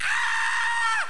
SCREAM03.mp3